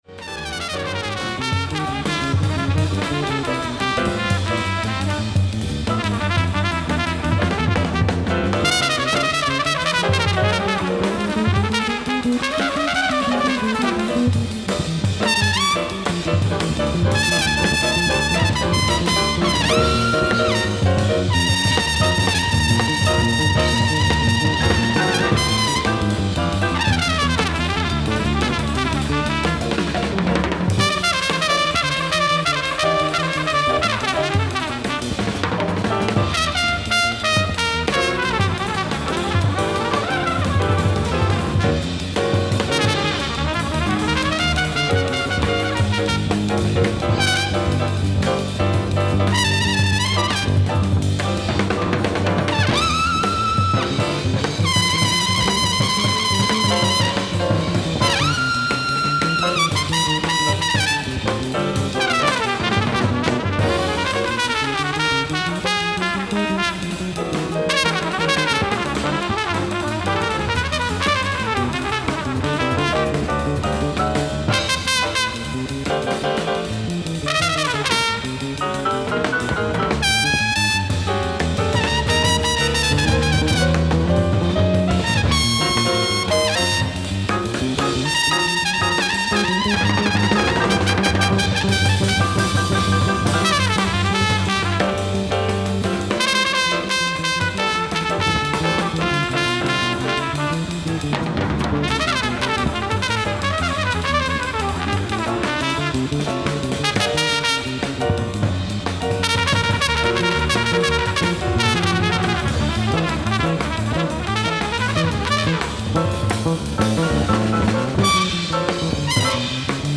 ライブ・アット・フィルハーモニー、ベルリン、ドイツ
※試聴用に実際より音質を落としています。